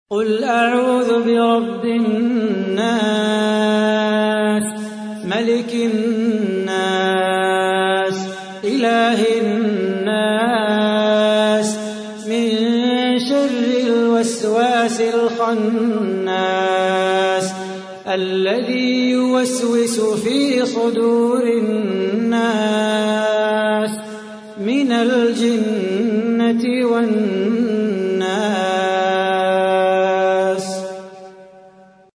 تحميل : 114. سورة الناس / القارئ صلاح بو خاطر / القرآن الكريم / موقع يا حسين